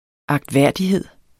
Udtale [ ɑgdˈvæɐ̯ˀdiˌheðˀ ]